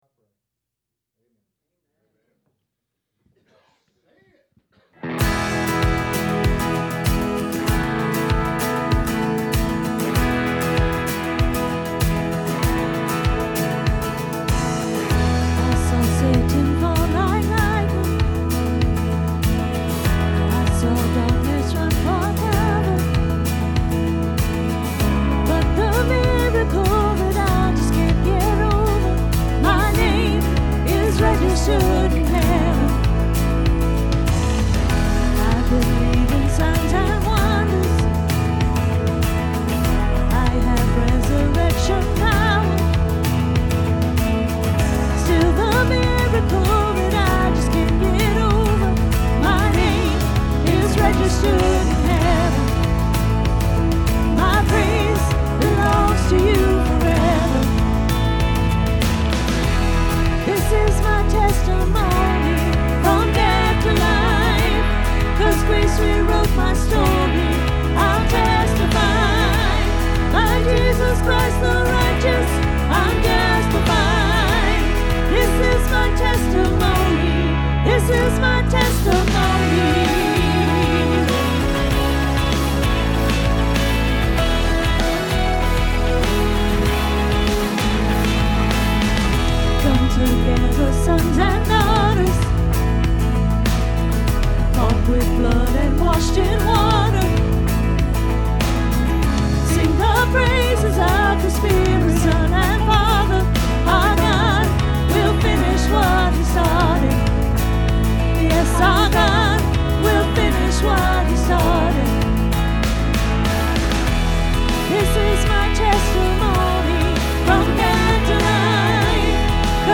Praise Team Audio